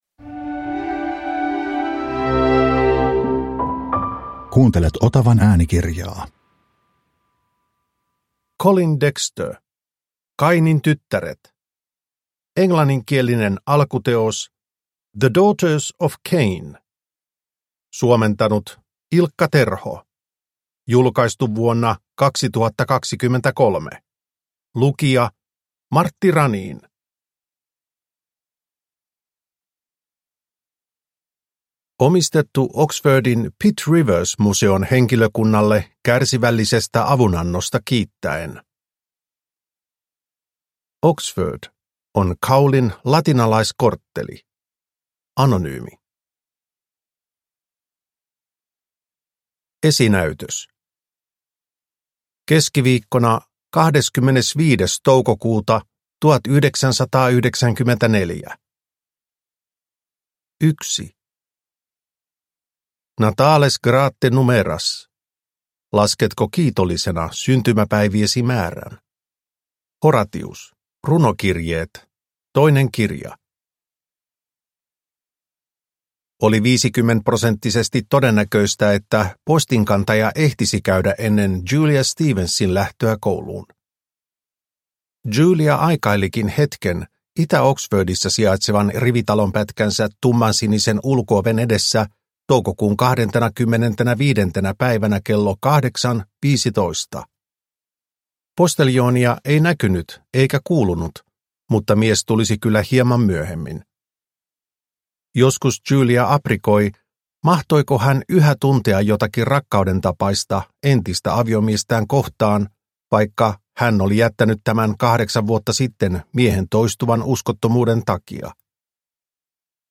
Kainin tyttäret – Ljudbok – Laddas ner